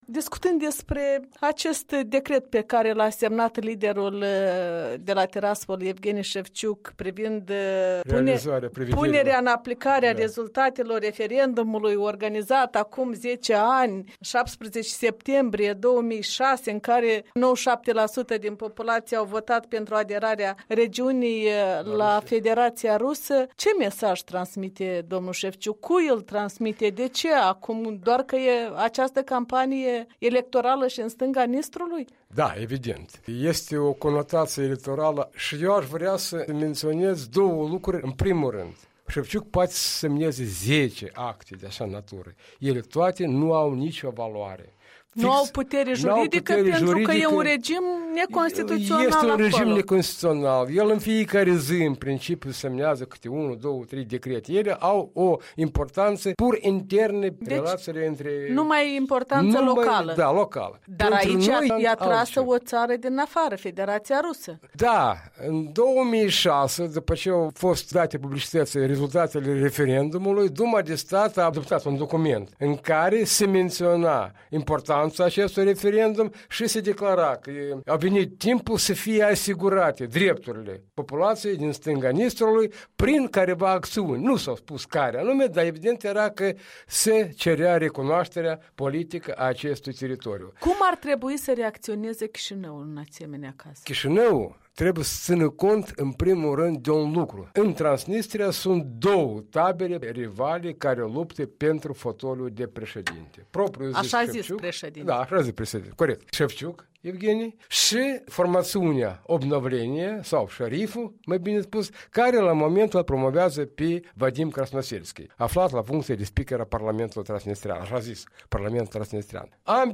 Intreviu cu un fost membru al Comisiei de Unificare și Control.